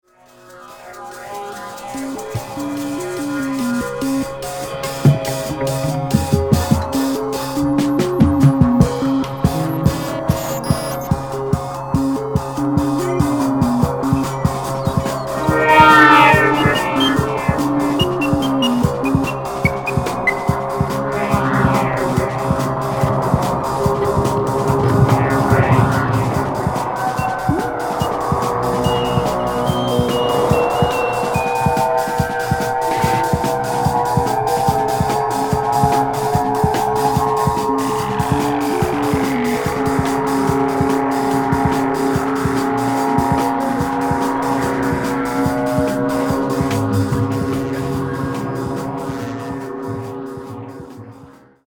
キーワード：電子音　コラージュ　ミニマル